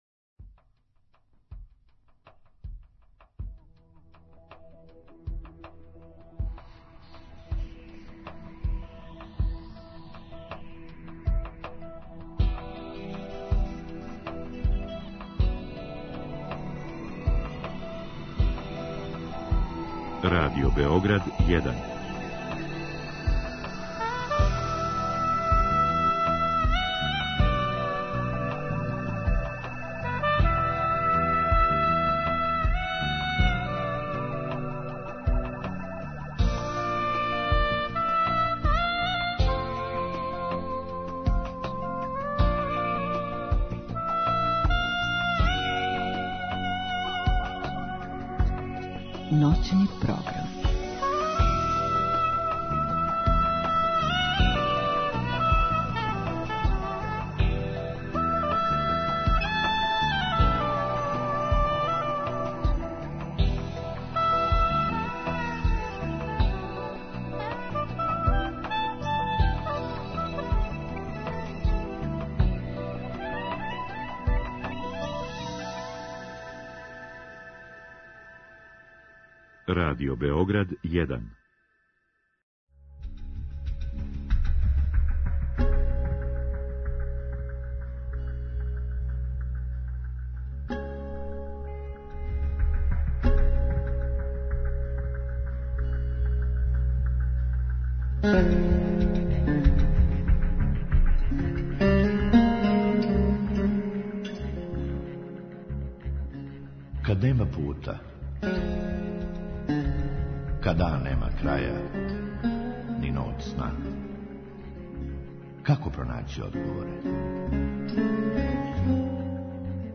У другом сату, слушаоци могу у директном програму поставити питање гошћи у вези са темом.